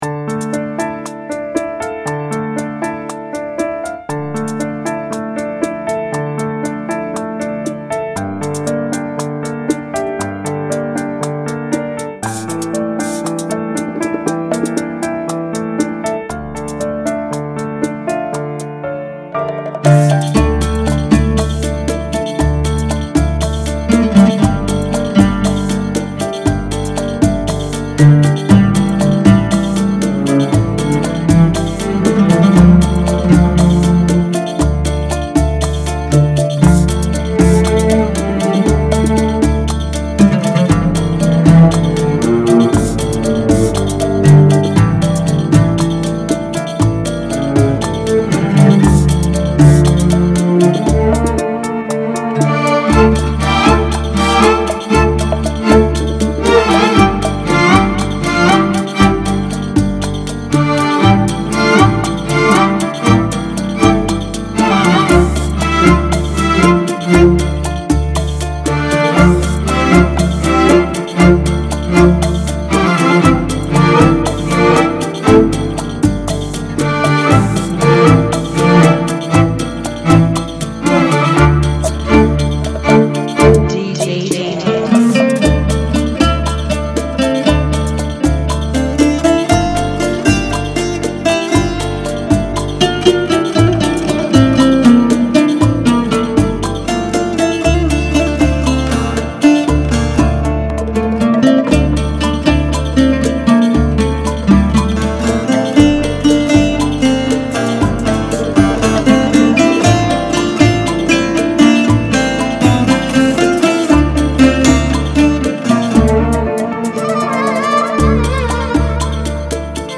118 BPM
Genre: Bachata Remix